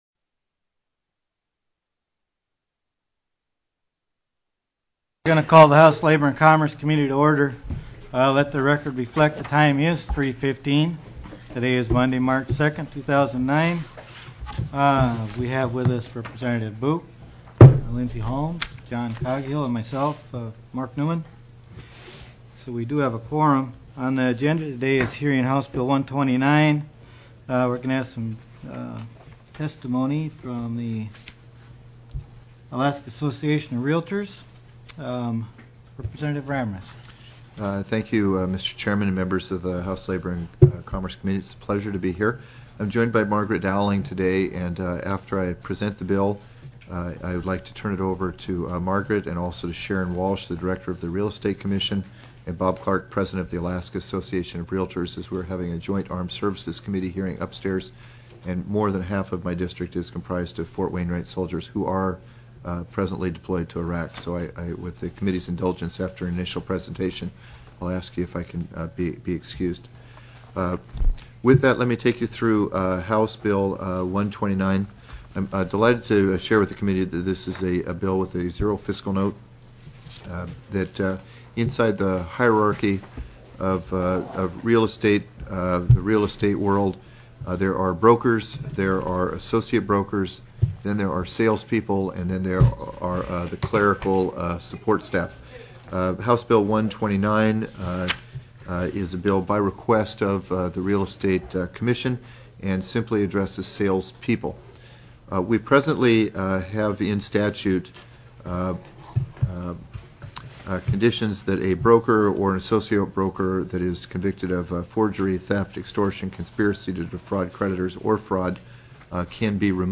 03/02/2009 03:15 PM House LABOR & COMMERCE
HB 129 REVOKING REAL ESTATE SALESPERSON LICENSES TELECONFERENCED